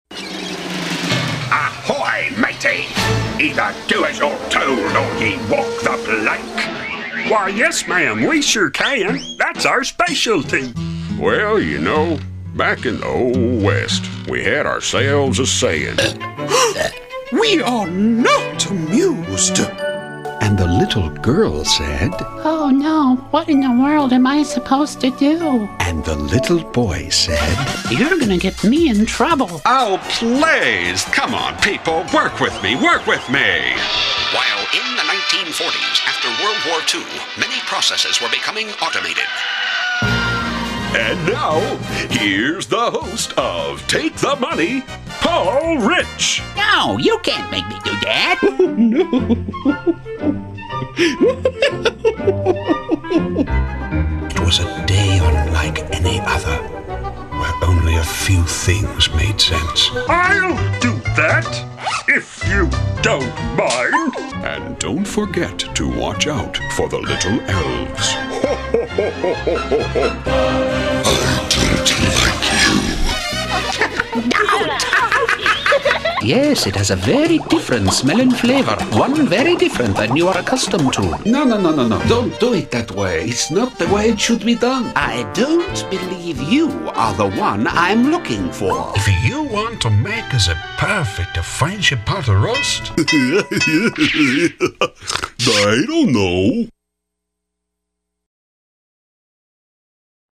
Benevolent, clean, polished, mature
Commercial Voiceover, Character